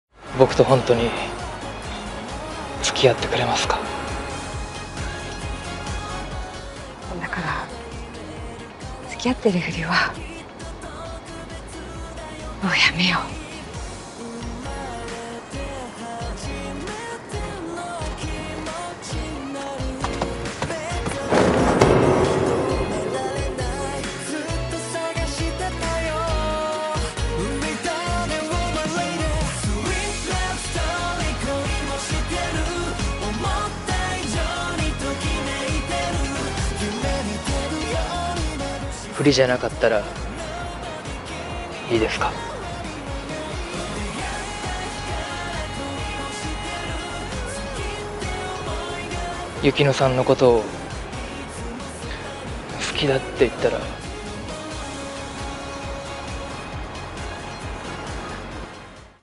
曲が入るタイミングが神すぎ…!!!!バックハグの破壊力やばすぎだろ!!!!来週が楽しみ…😭
バックハグ キュンとしますね 曲がかかるタイミングが良い👍